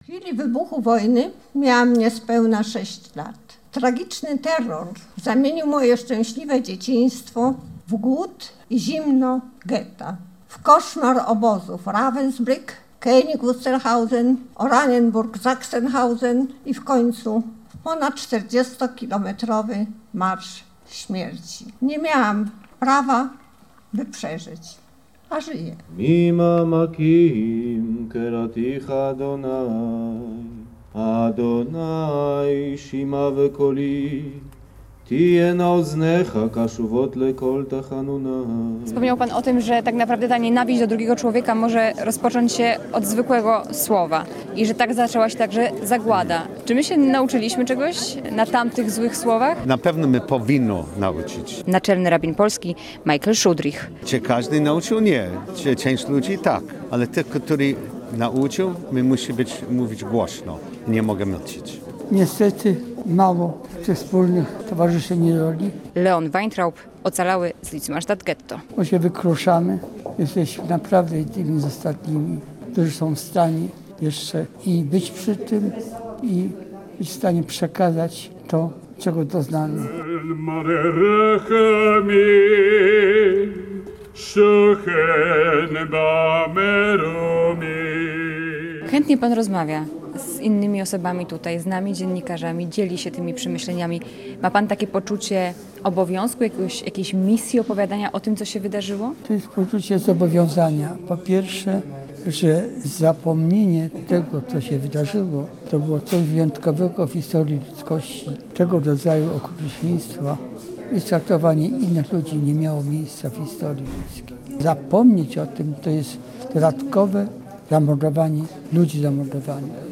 Dziś (27.08) w Łodzi odbyły się główne uroczystości związane z obchodami 77. rocznicy likwidacji Litzmanstadt Ghetto - drugiego co do wielkości i najdłużej istniejącego getta na ziemiach polskich.